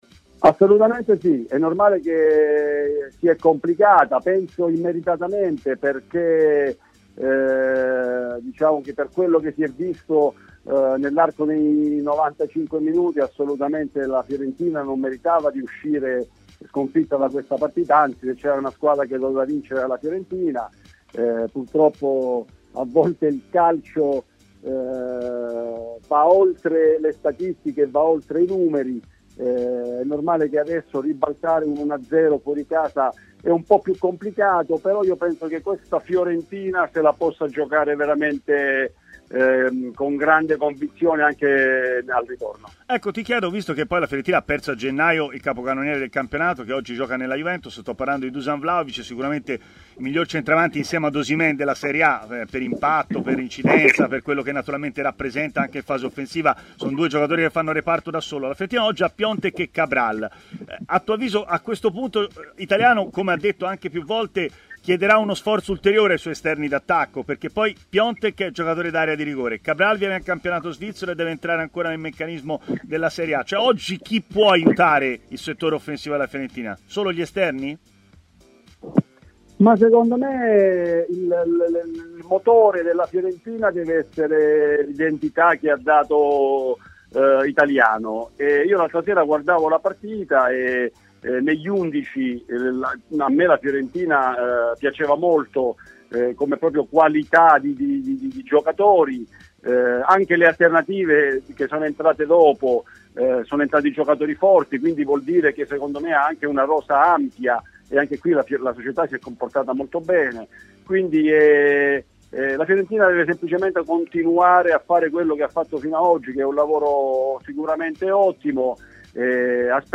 L'ex difensore della Fiorentina, Giuseppe Pancaro, ha parlato, durante la trasmissione "Stadio Aperto" su TMW radio,  anche dei viola e della possibilità di recuperare il risultato di Coppa contro la Juve: "Margini? Credo proprio di sì. Nella partita di mercoledì meritavano i viola di vincere. Adesso ribaltare un 1-0 fuori casa è più complicato, però penso che se la possa giocare al ritorno”.